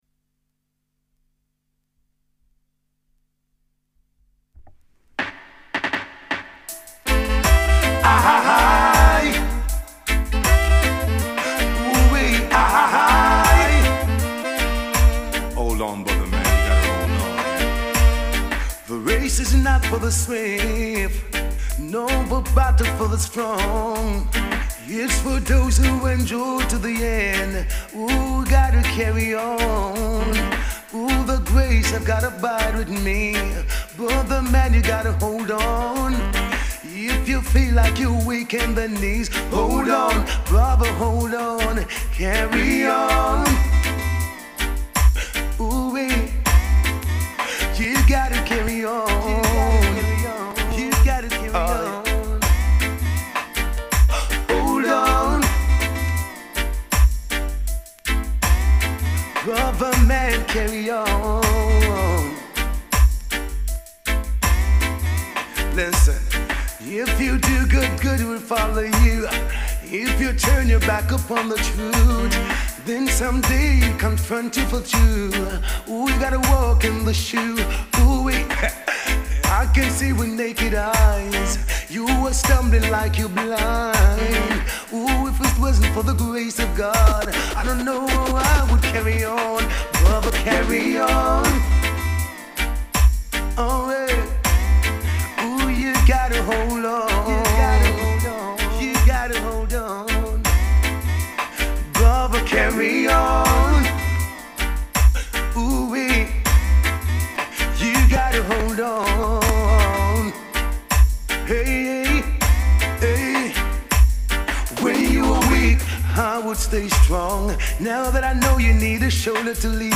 Vinyls, Pre-releases and Dubplates...